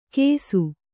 ♪ kēsu